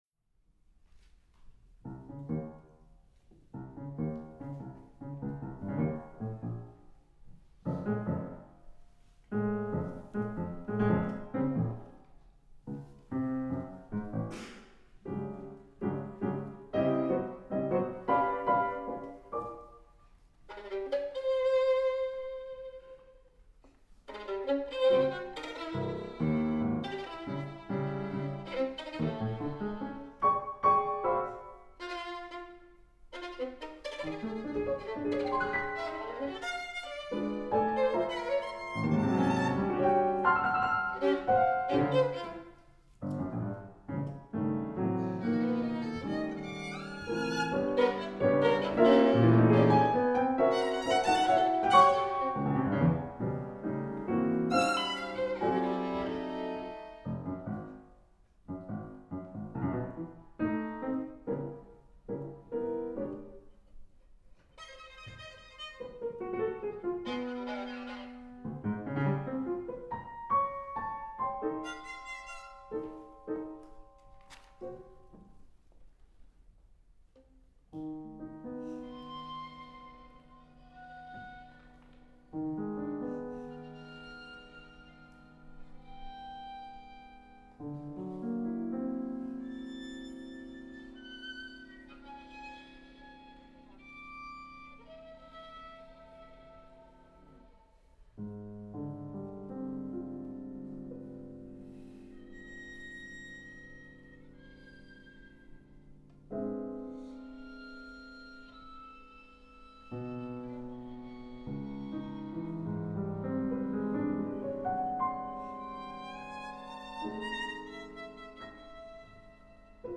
piano
Intermezzo: fleeting, then reflective